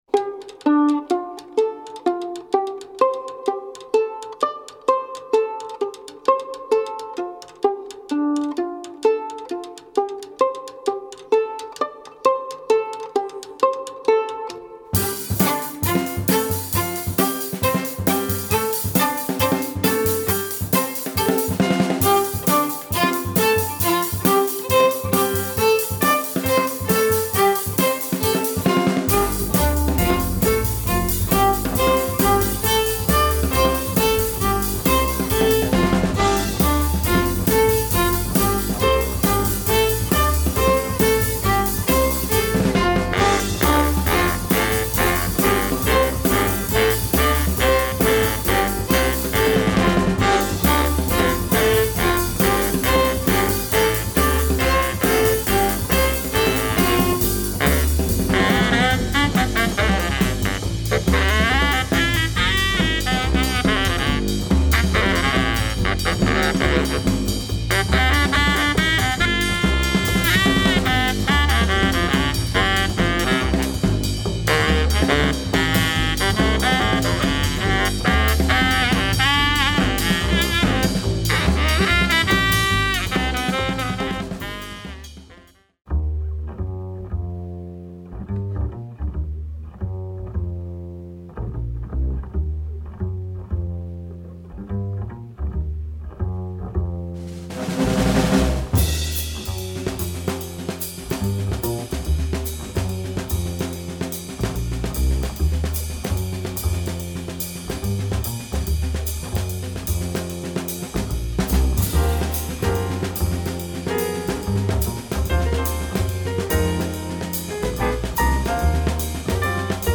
Free / spiritual jazz
Deep !